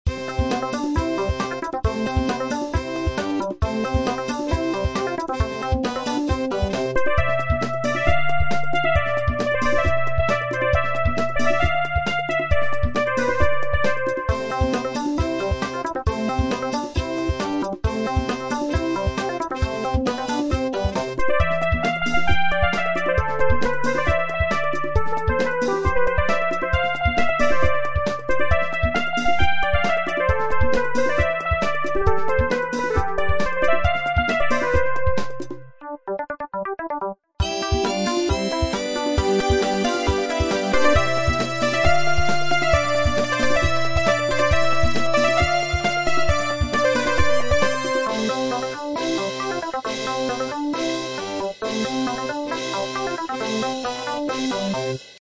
そんな作業の過程中、口ずさんでいた鼻歌をガレージバンドでへっぽこな曲にしてみたヨ。
ガレージバンドで作ったのにStudioSessionで作ったんじゃないかと思われるようなクオリティなのは、単に筆者の力量不足です。